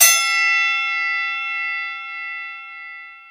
TM88 ReadyCrash.wav